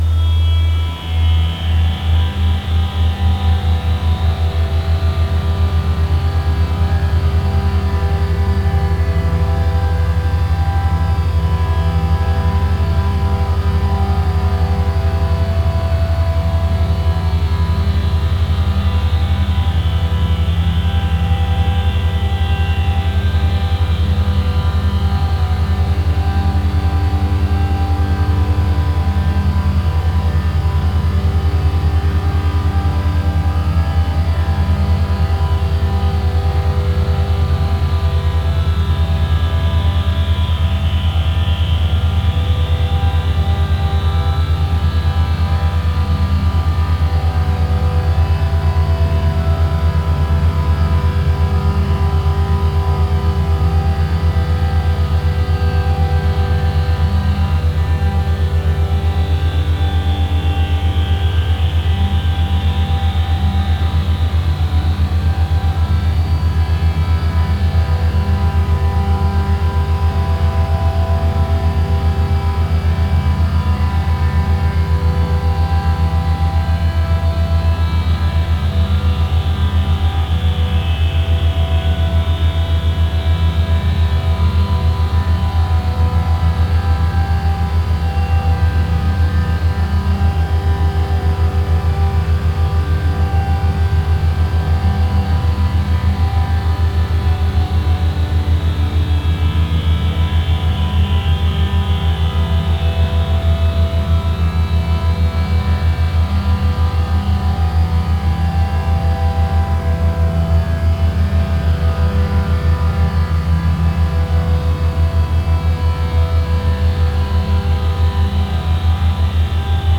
ambient.mp3